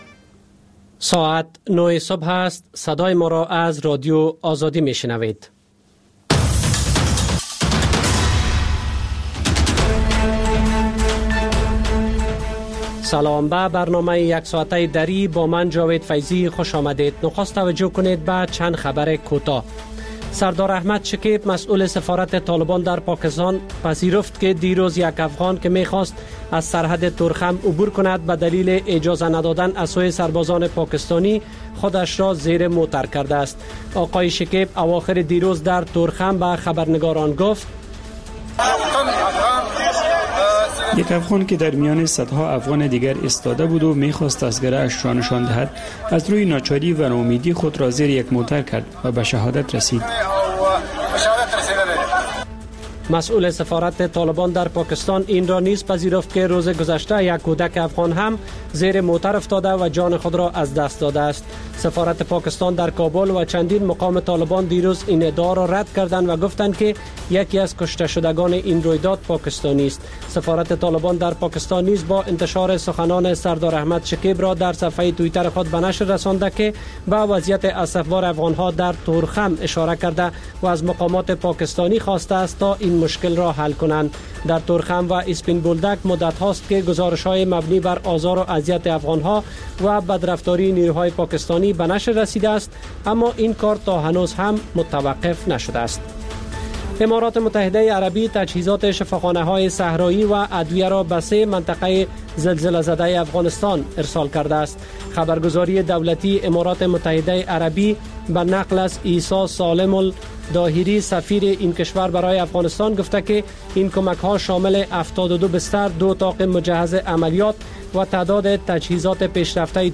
پخش زنده - رادیو آزادی